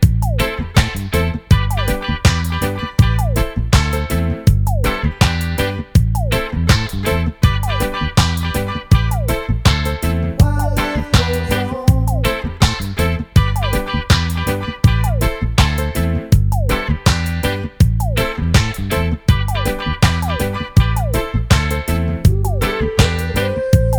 no Backing Vocals Reggae 3:25 Buy £1.50